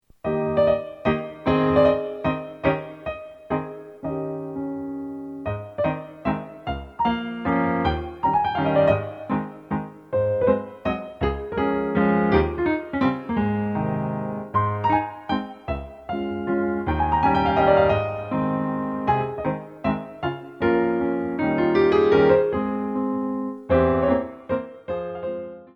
A Ballet Class CD